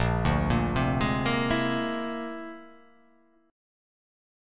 A#9b5 Chord